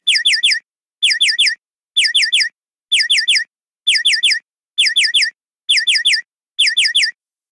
Efecto semáforo en ámbar indicando peligro próximo cambio a rojo
intermitente
semáforo
Señales: Tráfico
Sonidos: Ciudad